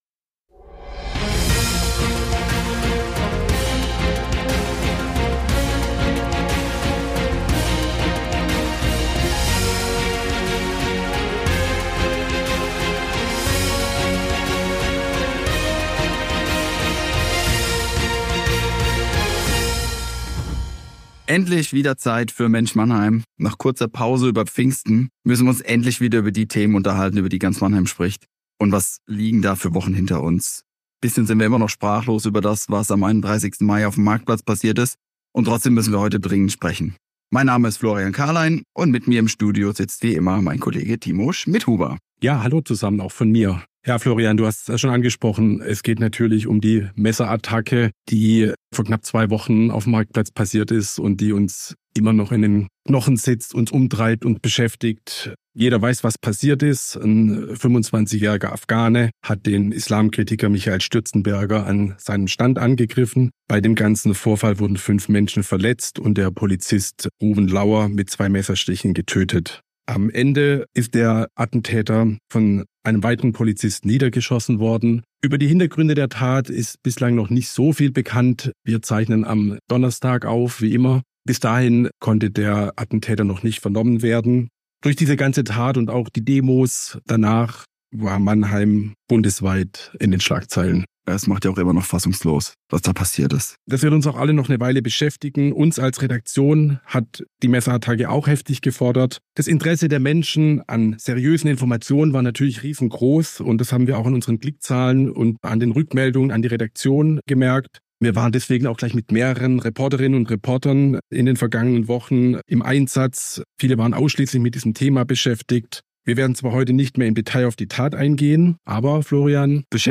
Außerdem verraten die Gäste die für sie überraschendsten Personalien der Kommunalwahl und tippen den kommenden Fußball-Europameister.